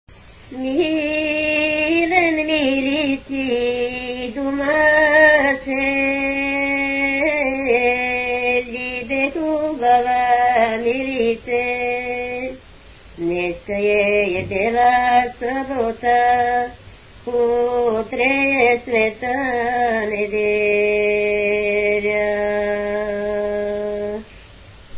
музикална класификация Песен
тематика Хайдушка
форма Четириредична
размер Безмензурна
фактура Едногласна
начин на изпълнение Солово изпълнение на песен
битова функция На седянка
фолклорна област Средногорие
начин на записване Магнетофонна лента
артефакти/типология Автентична